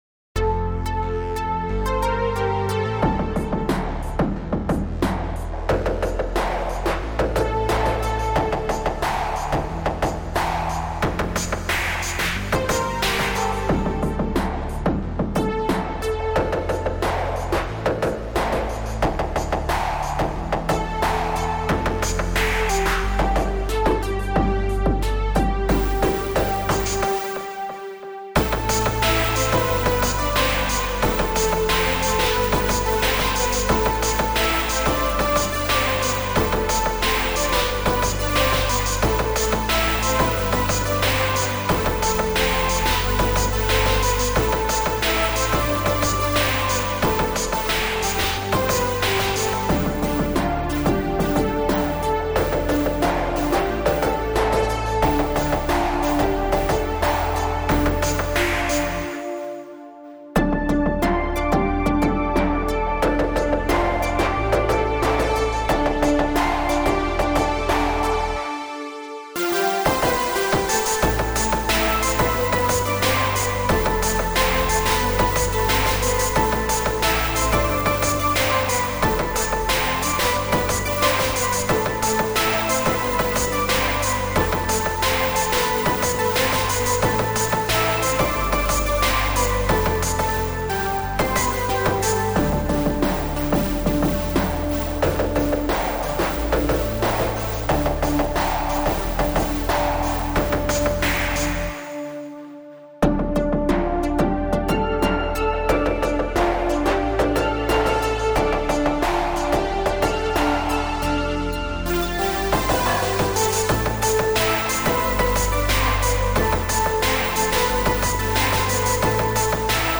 Electro-dance
triste ballade
Version instrumentale